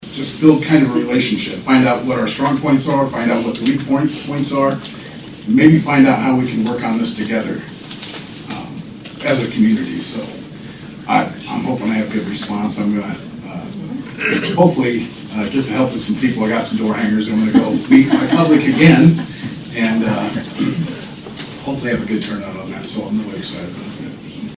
(Atlantic) Atlantic Fourth Ward City Councilman Shawn Sarsfield, speaking at Wednesday’s City Council meeting, invites fourth ward constituents to a meeting from 6:00 p.m. to 7:30 p.m. at the Venue on June 4 to get to know him and the opportunity to exchange views on critical issues facing the City, or precisely issues affecting the Fourth Ward.